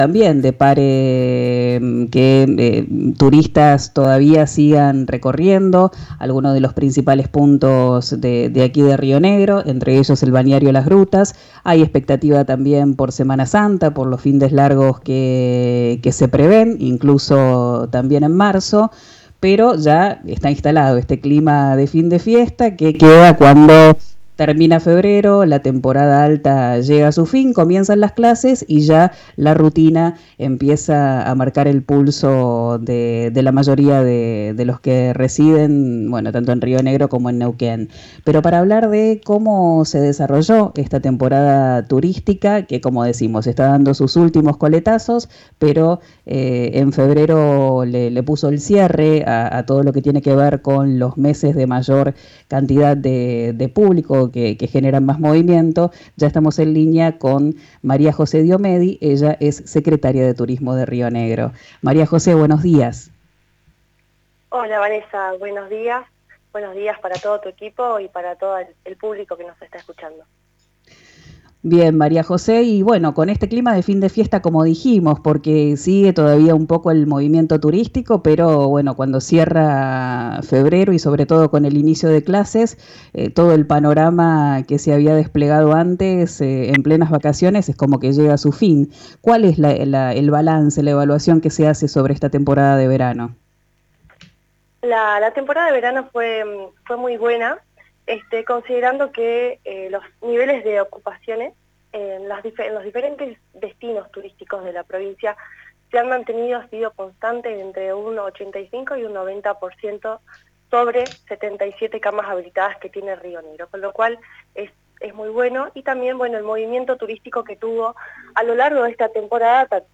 La secretaria de Turismo de Río Negro, María José Diomedi hizo un balance muy positivo sobre la actividad en la región en RÍO NEGRO RADIO.